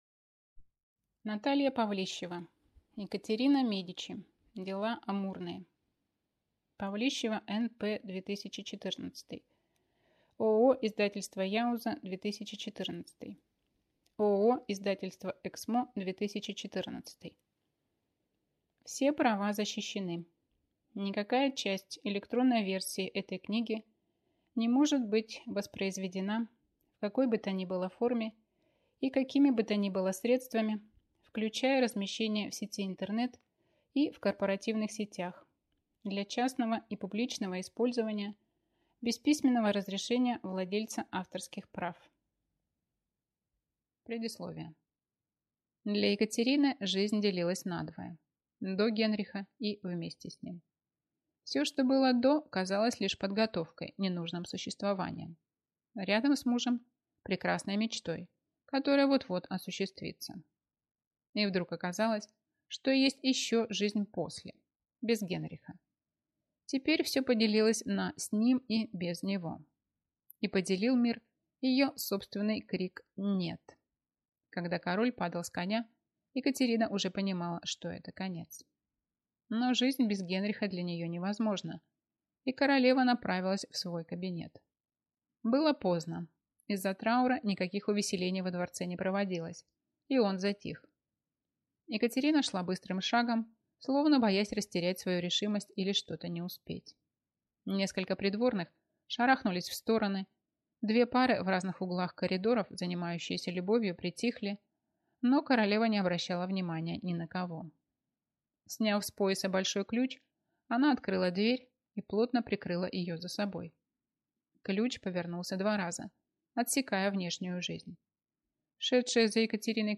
Аудиокнига Екатерина Медичи. Дела амурные | Библиотека аудиокниг